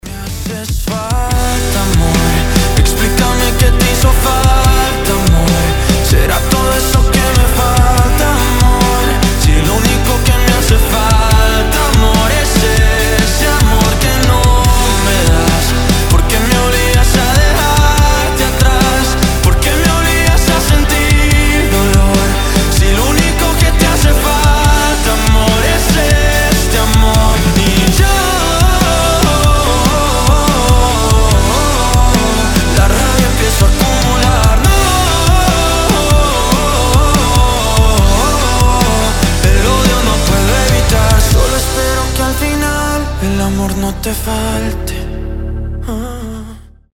мелодичные
красивый мужской вокал
Pop Rock
баллада